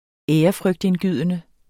Udtale [ ˈεːʌfʁœgdenˌgyðˀənə ]